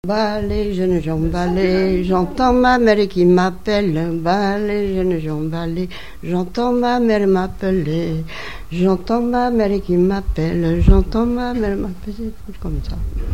danse : branle : courante, maraîchine
Catégorie Pièce musicale inédite